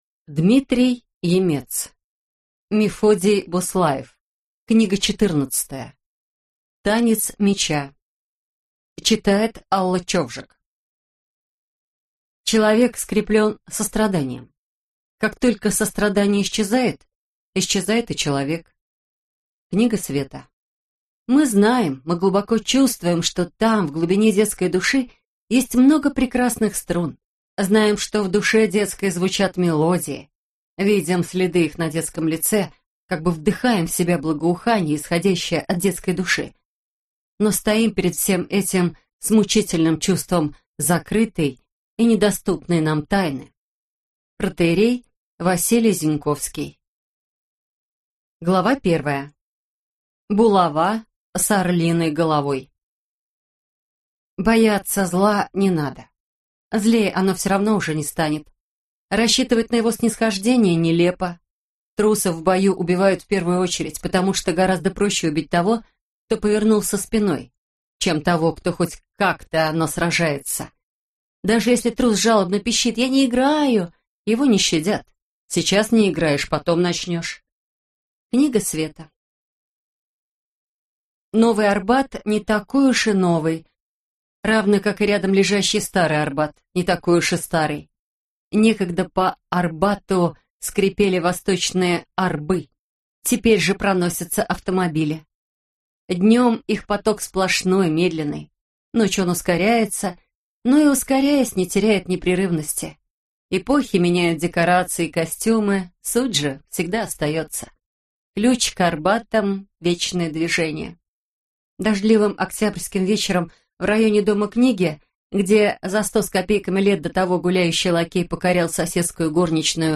Аудиокнига Танец меча | Библиотека аудиокниг